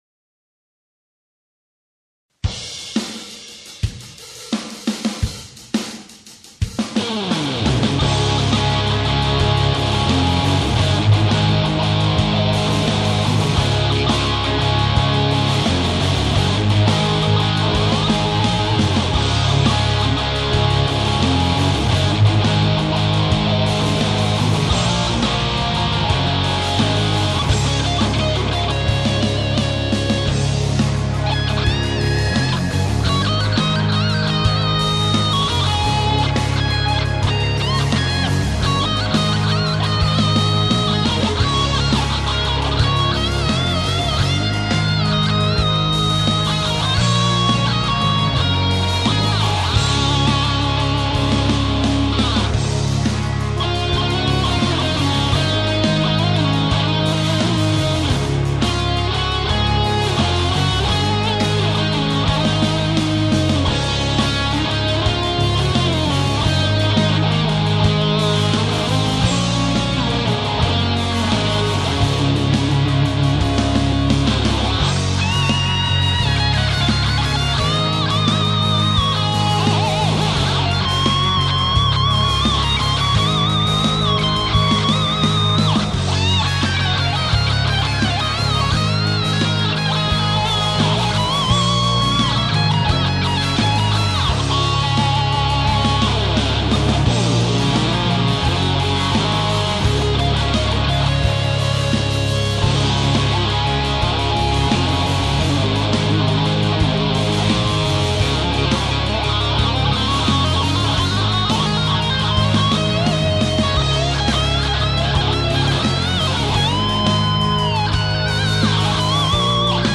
Darum benutze ich Hintergrundmusik, wenn ich spiele.